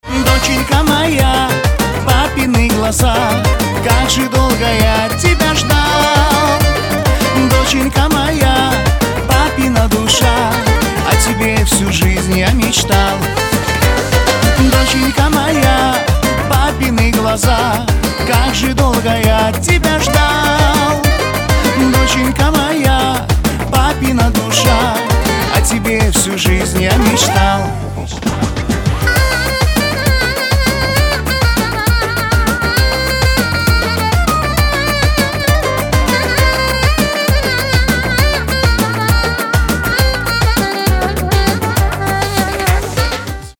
Рингтоны шансон
Кавказские